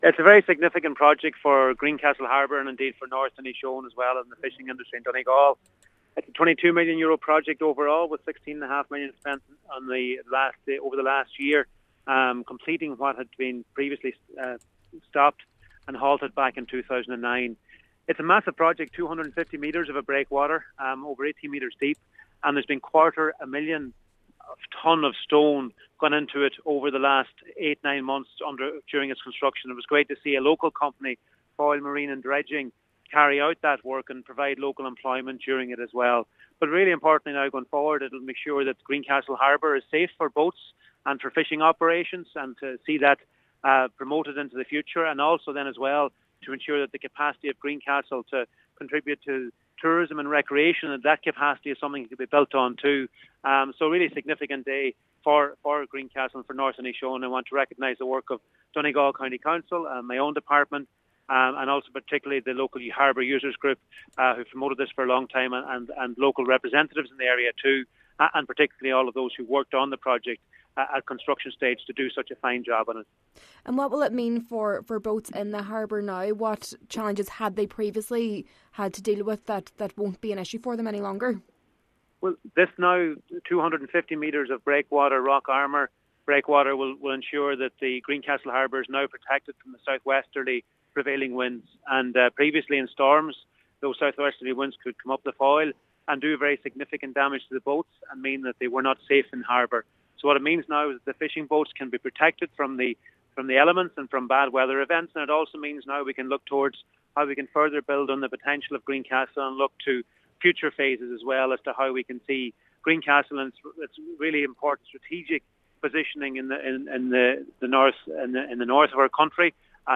Minister McConalogue says the breakwater will provide protection to boats docked in the harbour: